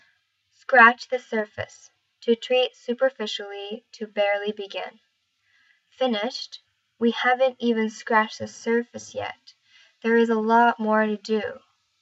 ただしこれは、表層にとどまる浅い傷であることから、何かの課題や問題について、表面的な、浅い対応をするというイディオムとして使われる表現です。 英語ネイティブによる発音は下記のリンクをクリックしてください。